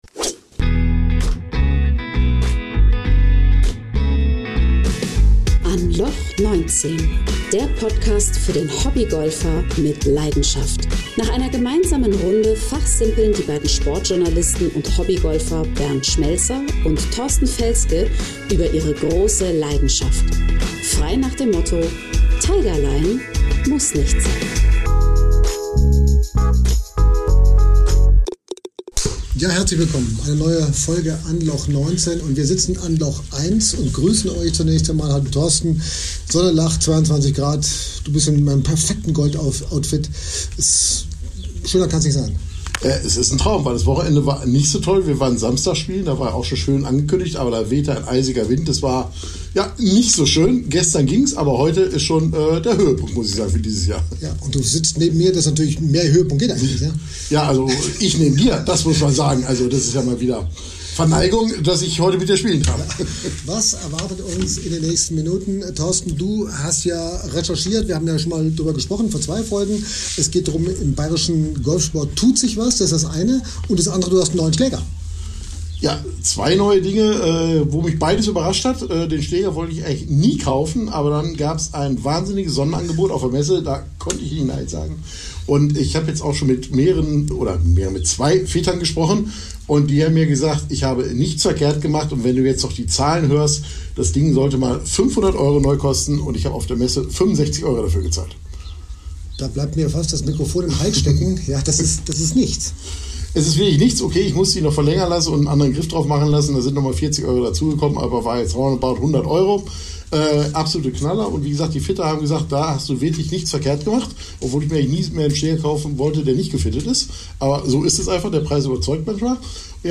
Wir erleben ihn gemeinsam auf einer 9-Loch-Golfrunde und stellen am Ende fest: Nein, zu viel wollen wir nicht vorab verraten.